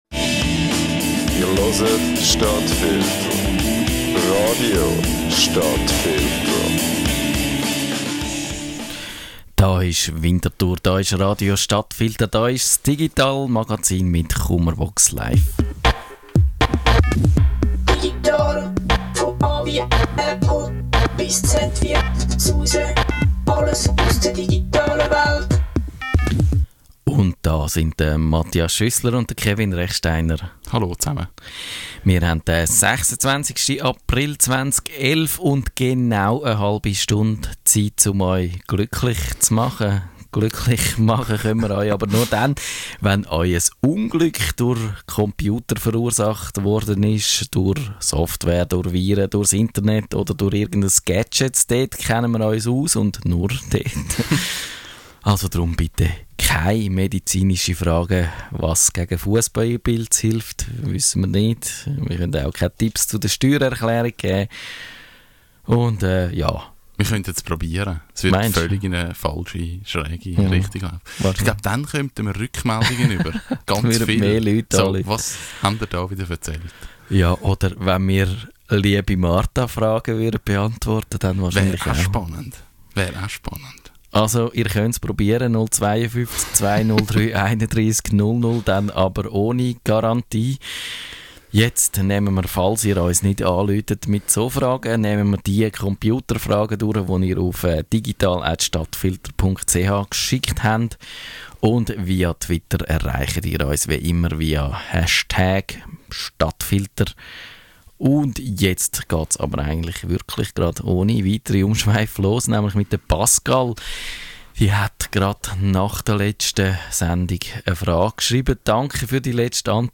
Kummerbox Live, dieses Mal mit E-Book- und Hörbuch-Tipps.